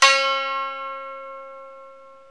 ETH XKOTO 04.wav